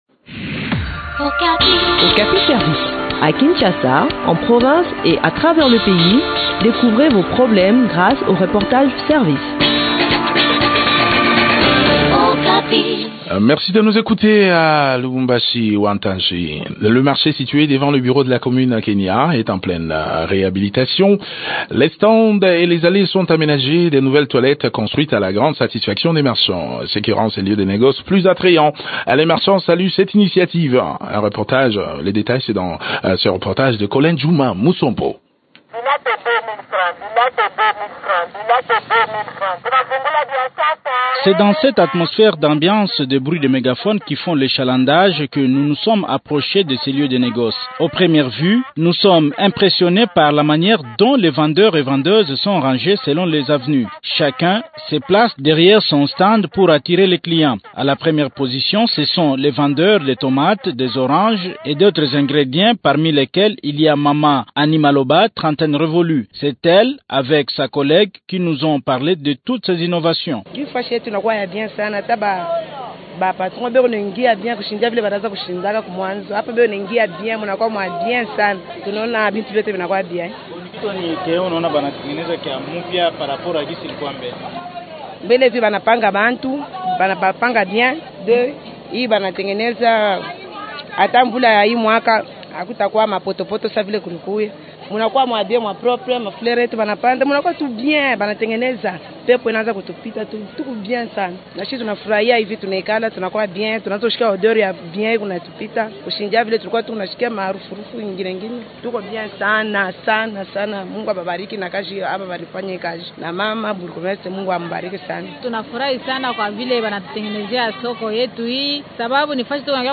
Le point sur les conditions de réinstallation des marchands dans cet entretien